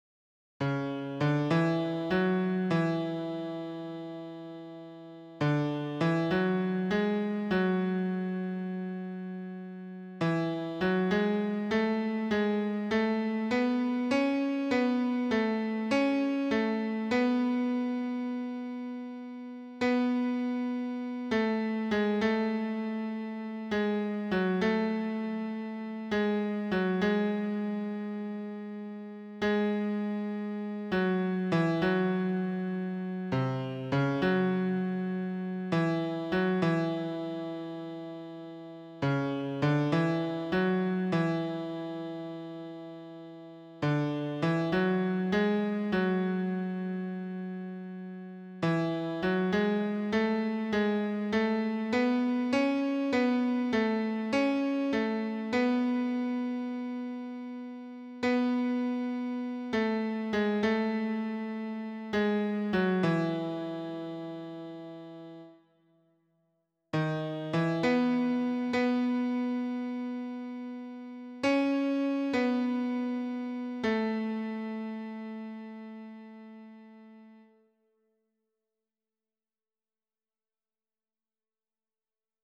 esse_seu_olhar_-_baritono[50291].mp3